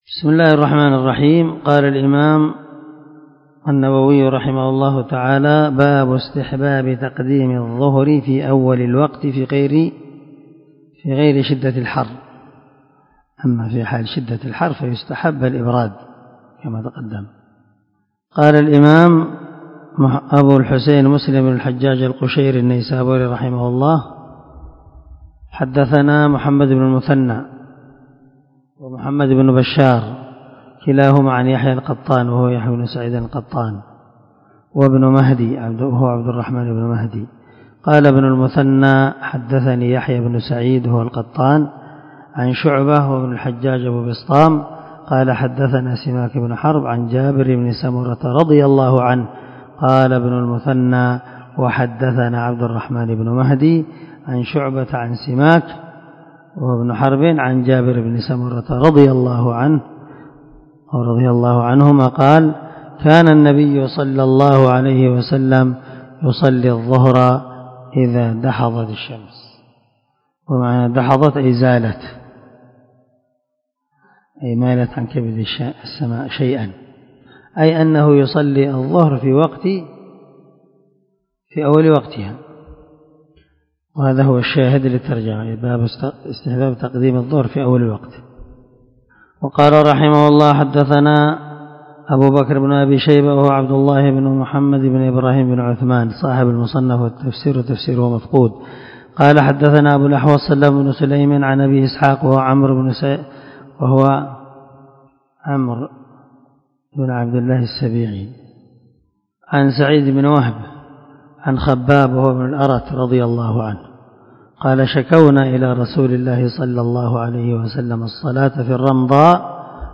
سلسلة_الدروس_العلمية